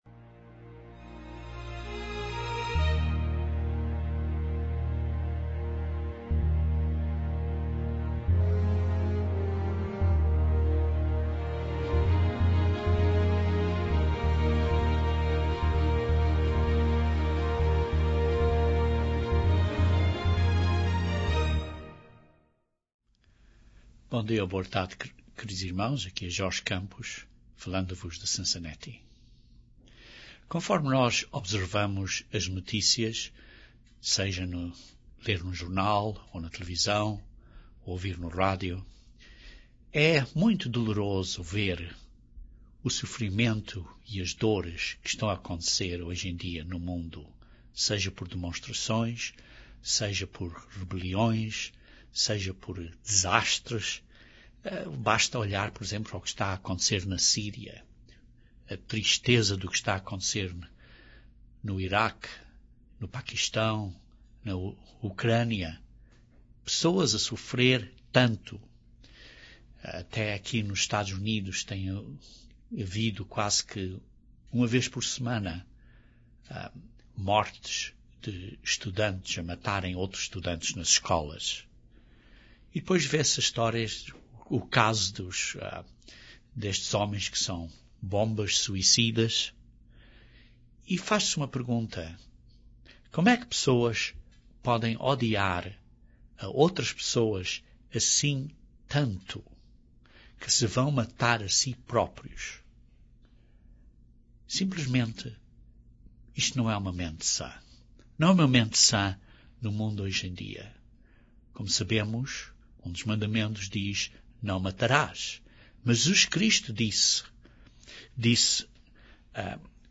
Este sermão analisa como satanás veio a ter o controle na sociedade que tem, e que pontos de controle é que ele usa.